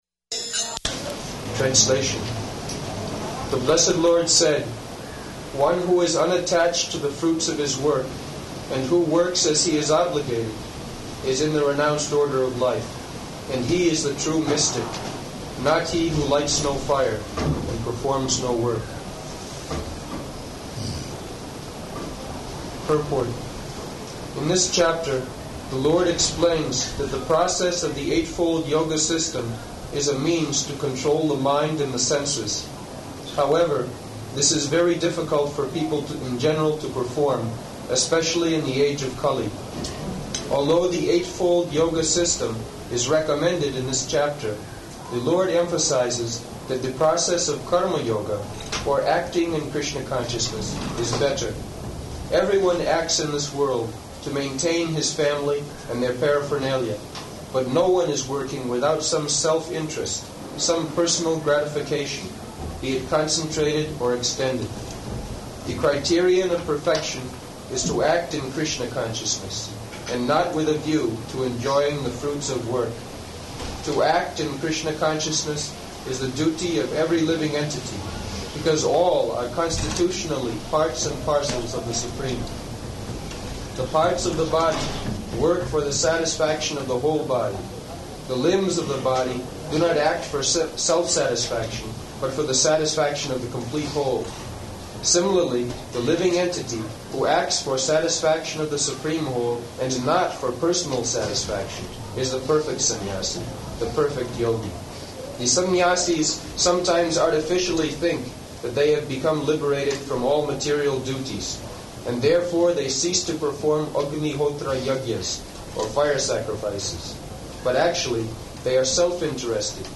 Type: Conversation
Location: Rishikesh